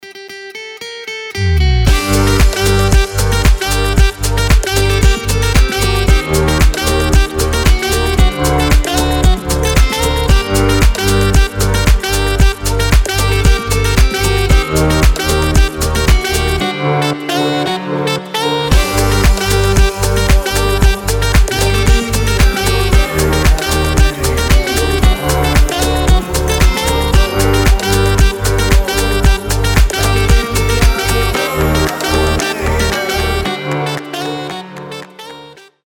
гитара
релакс
Саксофон
расслабляющие
house
Chill
Приятная летняя музыка